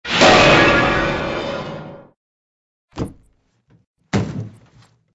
AA_drop_piano.ogg